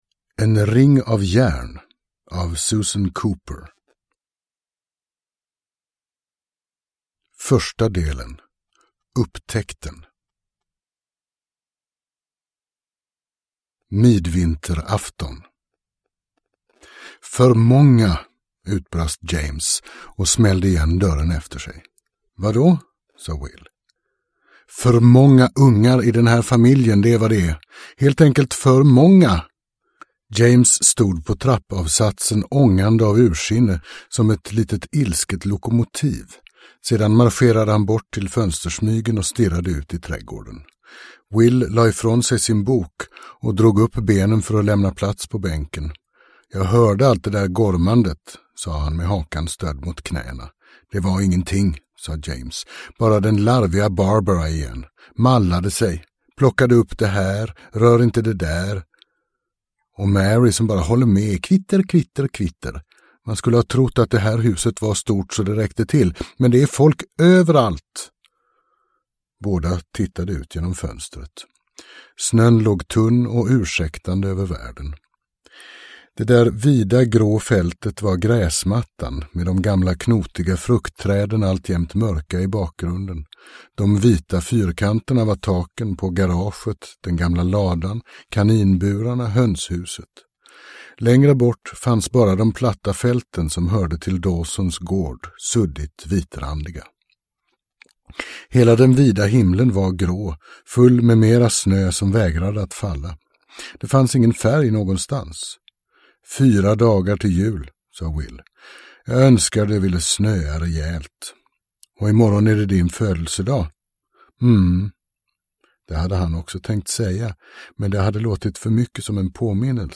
En ring av järn – Ljudbok – Laddas ner